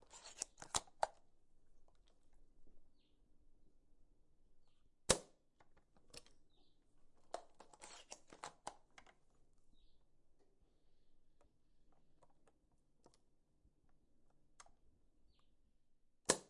枪械 " 308步枪01
描述：变焦H4记录了一支雷明顿700.308步枪在户外射击场的情况。步枪爆炸后的响声是射击口的钢支架。
标签： 步枪 射击
声道立体声